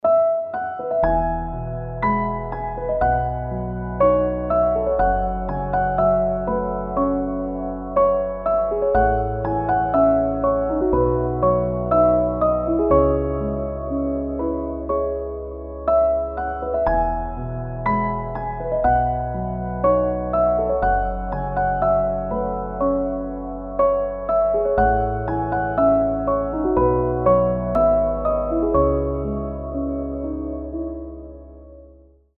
спокойные
без слов
красивая мелодия
пианино
Ambient
нежные
Красивая расслабляющая фоновая музыка